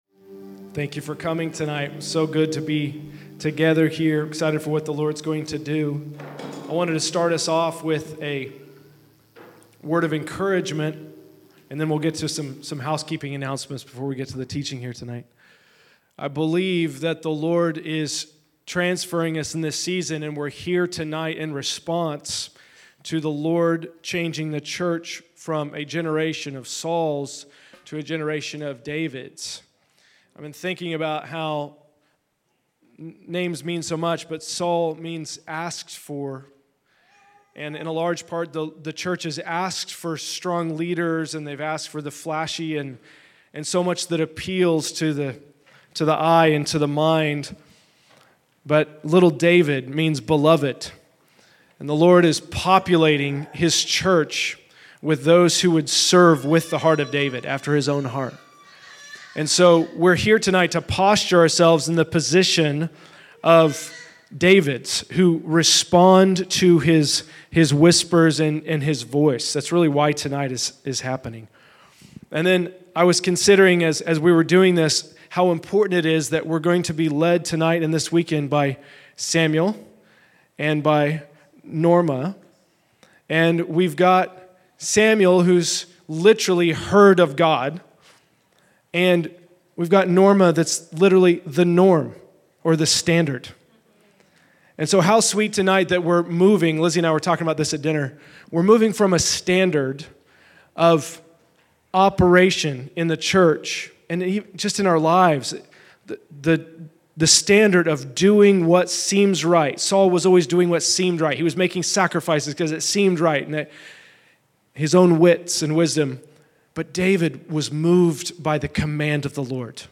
Hearing Conference - Introduction: From Sauls to Davids